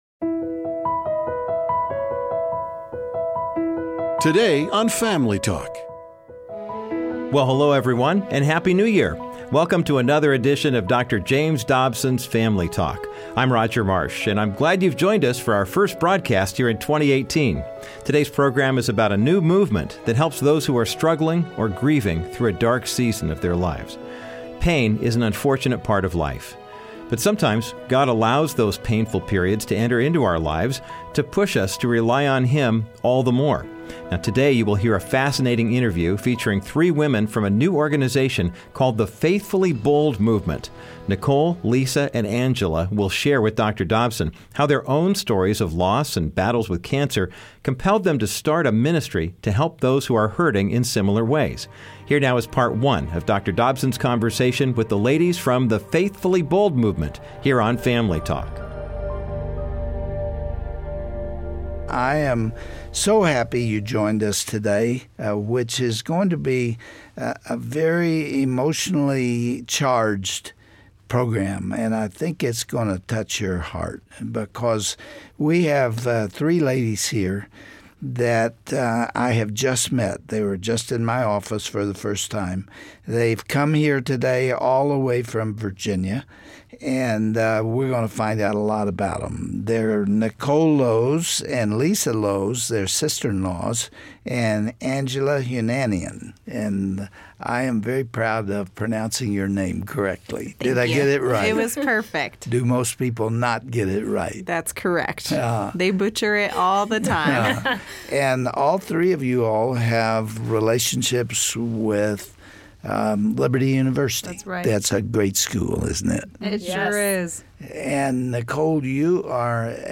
On todays episode, Dr. Dobson will talk with the founders of a new ministry called Faithfully Bold Movement. These women will describe how their own personal tragedies compelled them to help others who are facing similar situations.